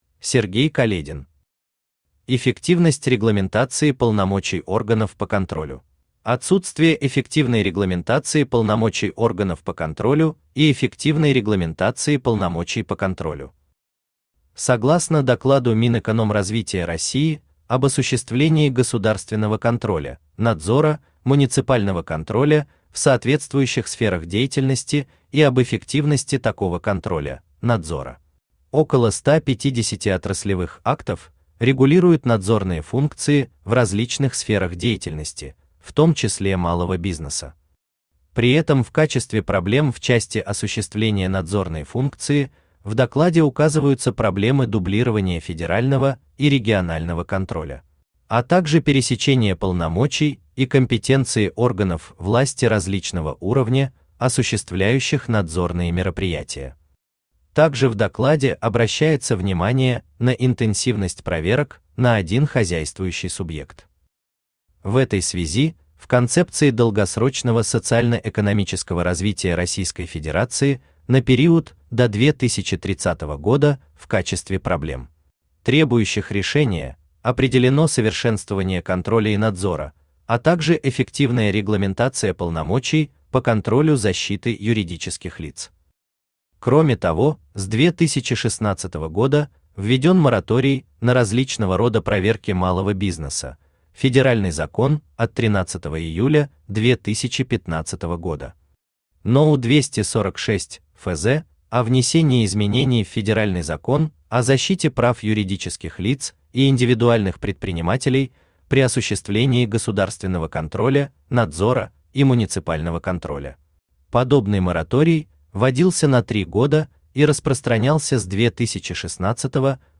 Аудиокнига Эффективность регламентации полномочий органов по контролю | Библиотека аудиокниг
Aудиокнига Эффективность регламентации полномочий органов по контролю Автор Сергей Каледин Читает аудиокнигу Авточтец ЛитРес.